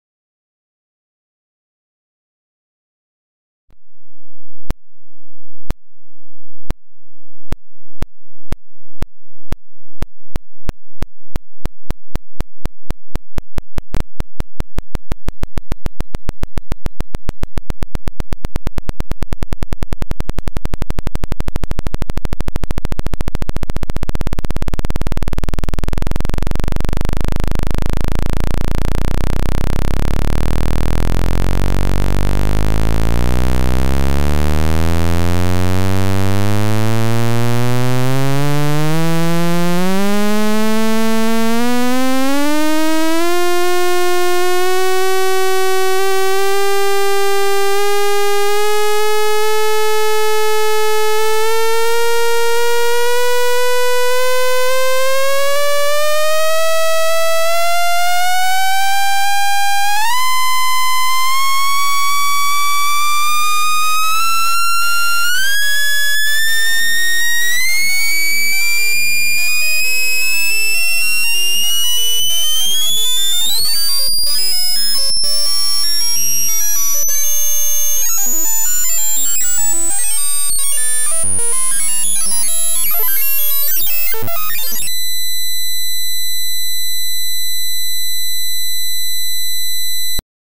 Аудиофайлы генерируют специфические частоты, которые помогают вытолкнуть загрязнения без механического вмешательства.
Звук очистки динамиков от пыли